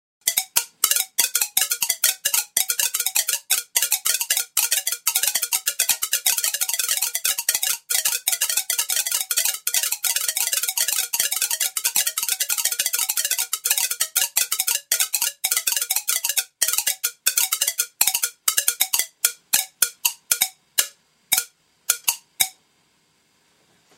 Sounds improvised by electrons...
As the number of tubes increases, the interaction effect tends to avarage out, leading to a more regular beat for each of the tubes, but the larger number of different notes tends to disguise the regular beat of each tube and produce a more random-sounding 'tune' (jazz mode - nice).
MP3 (375K) (Note that the audio compression artefacts on the MP3 version, tend to turn the very sharp clickiness of the sparks into a softer 'fuzzy' sort of noise, the sound on the AVI and Mpeg versions is more realistic).
Acrylic tube was used so the sparks were nicely visible, and a set of tubes were cut to resonate at musical notes A (440hz) upwards.
sparkophone.mp3